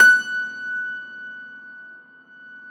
53e-pno20-F4.aif